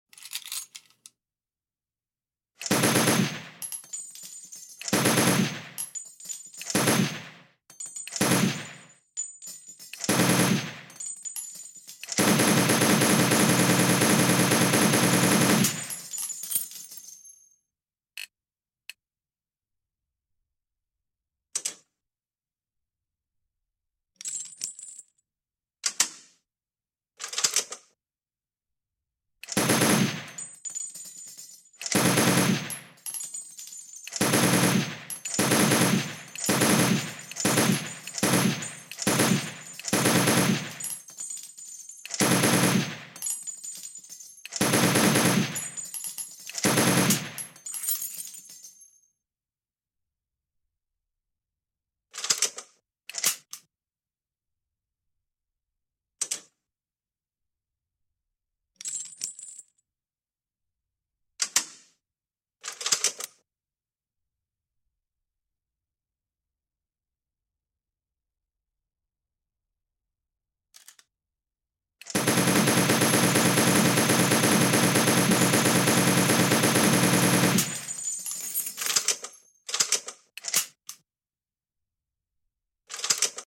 PKM MACHINE GUN 🏴‍☠ sound effects free download